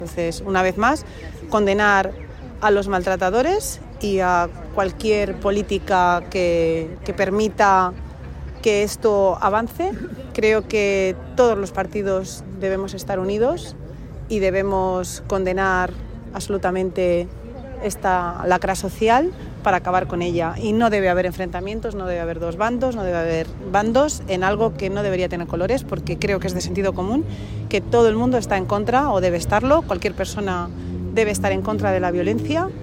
En la concentración mensual contra la violencia de género
La concejal de Inclusión, Asuntos Sociales, Familia y Mayores, Marisol Illescas ha lamentado hoy una vez más el asesinato de 47 mujeres, víctimas de violencia de género en la manifestación mensual que se celebra en el parque de la Vega.